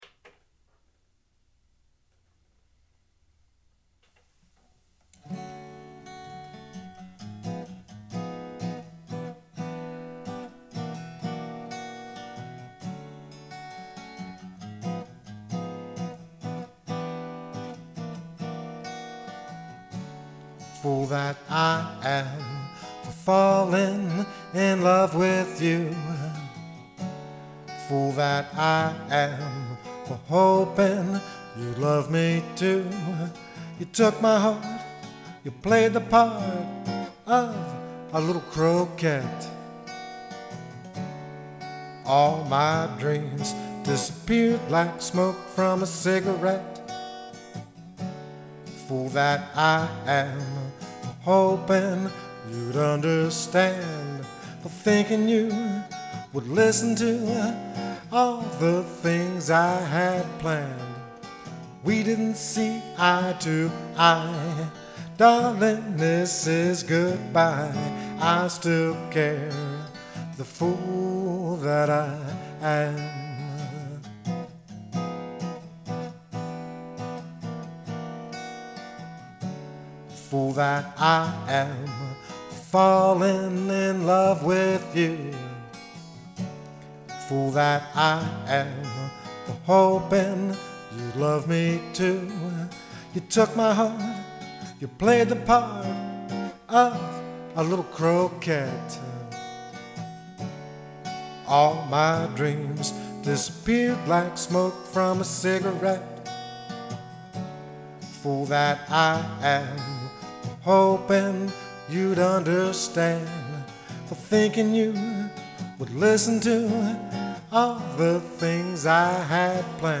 solo Vocalist and Acoustic Guitarist